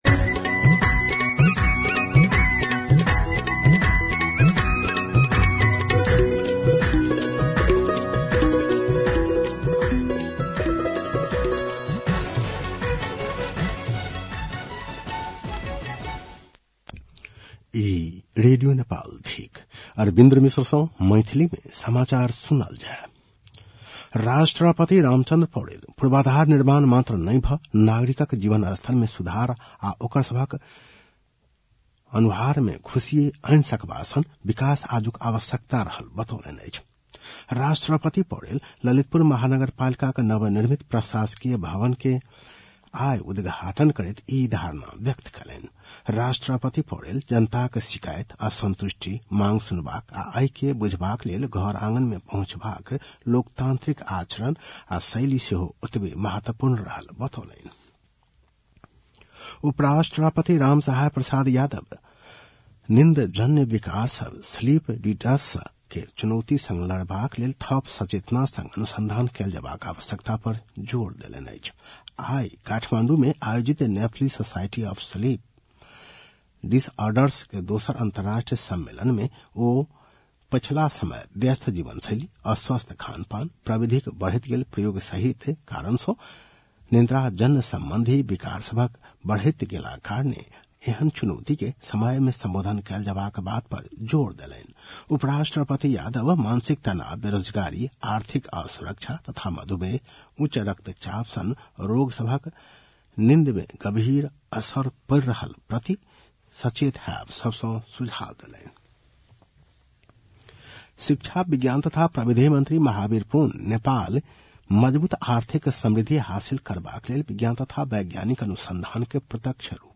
मैथिली भाषामा समाचार : २० मंसिर , २०८२